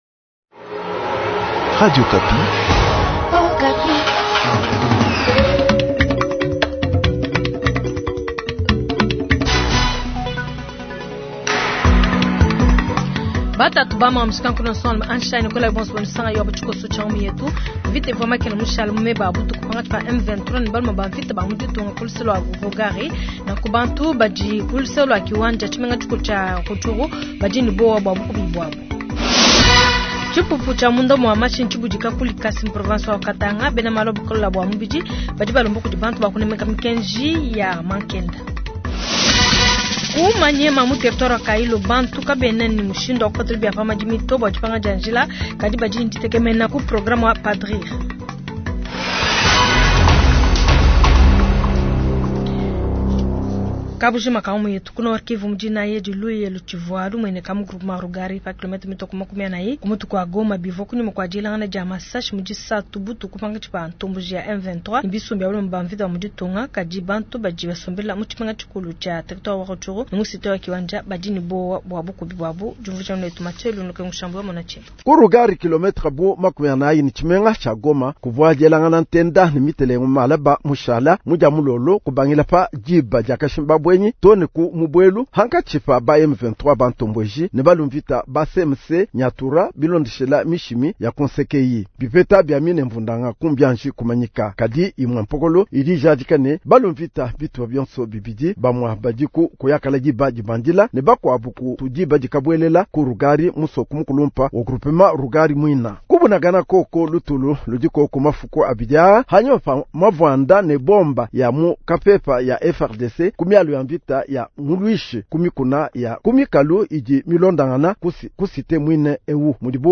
Journal soir